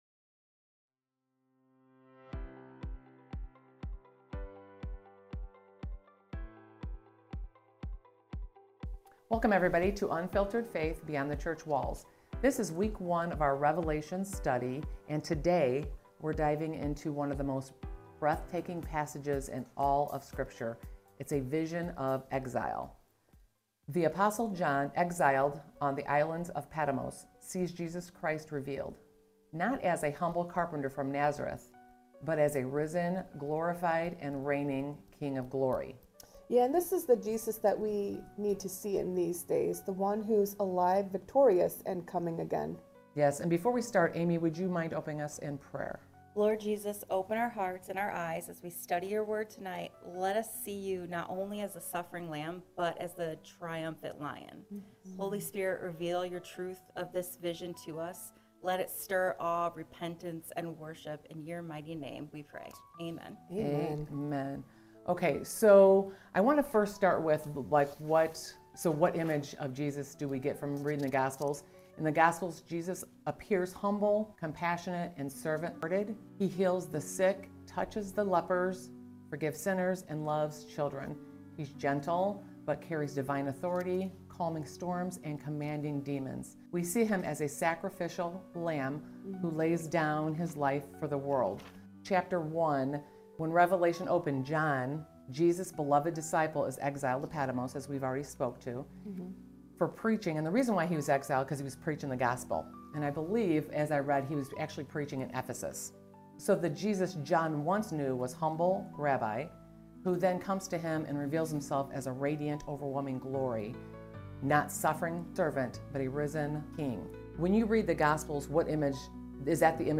If Revelation has ever intimidated you, this conversation will help you see it as a book of hope, victory, and worship—not doom.
Disclaimer: We’re not theologians or scholars—just Jesus-loving women studying Scripture and inviting you to grow with us.